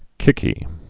(kĭkē)